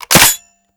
lowammo_dry_shotgun.wav